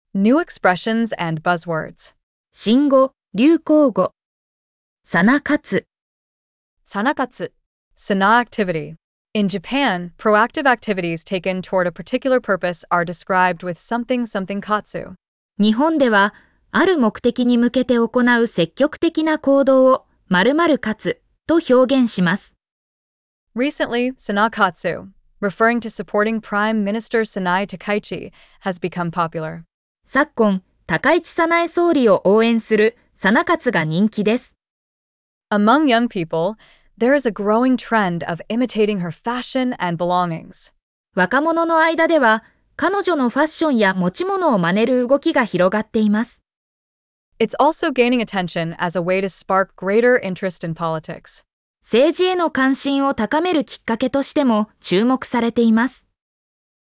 🗣 pronounced: Sanakatsu